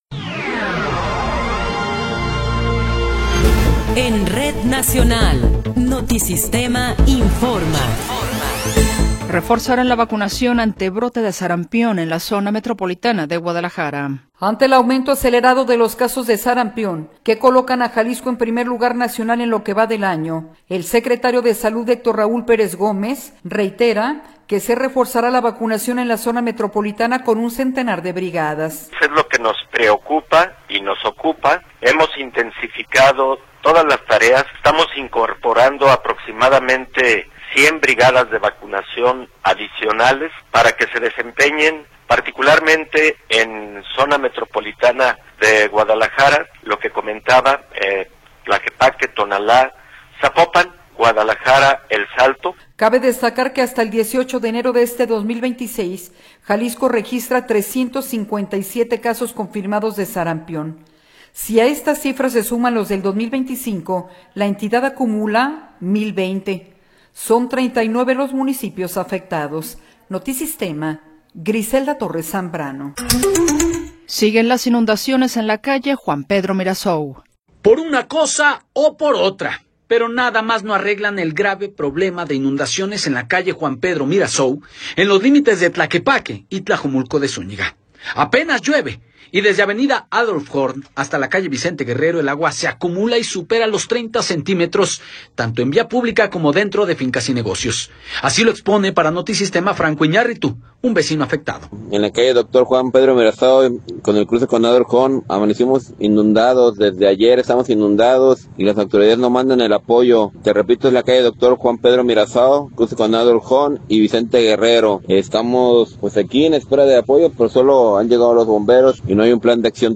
Noticiero 17 hrs. – 19 de Enero de 2026
Resumen informativo Notisistema, la mejor y más completa información cada hora en la hora.